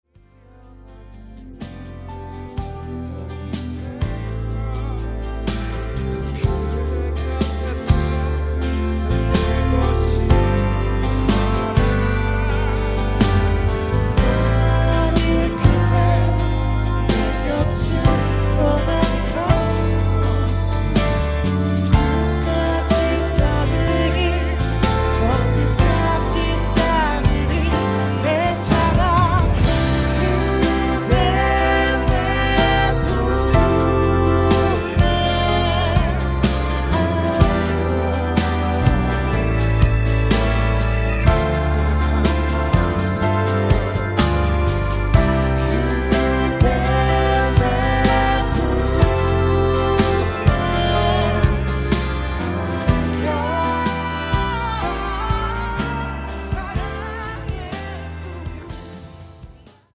음정 원키 6:19
장르 가요 구분 Voice MR